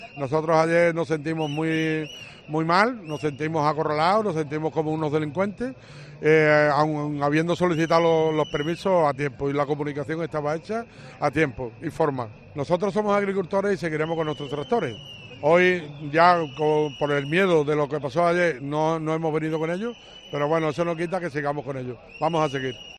agricultor onubense